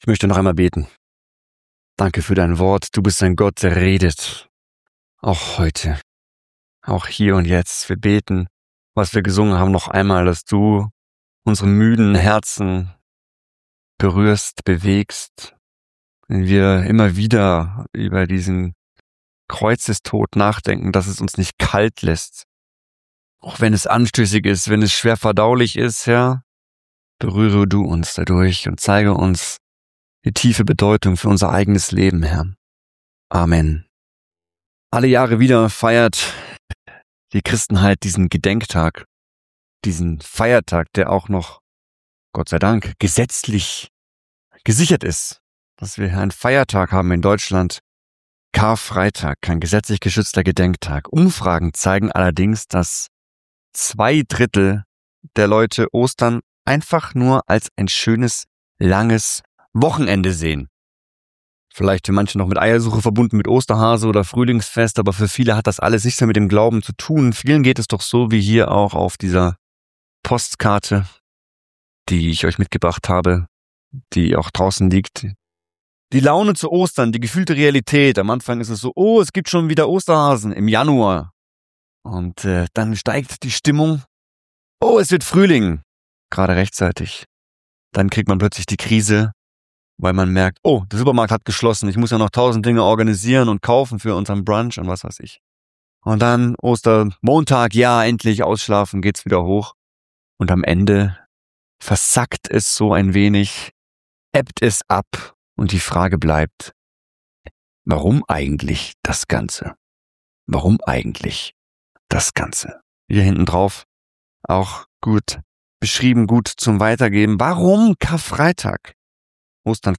Predigtreihe Exodus - Part 3